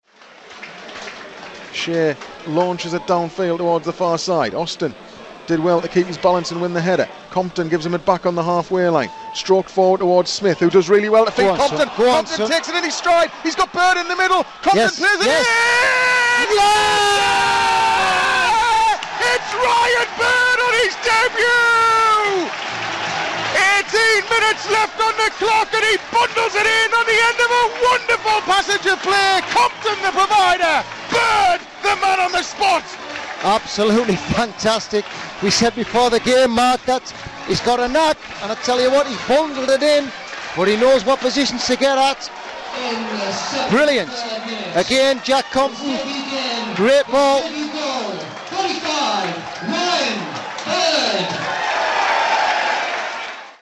Listen back to how it sounded on Pools Player's live commentary at the moment Ryan Bird struck the winner against AFC Wimbledon. Commentary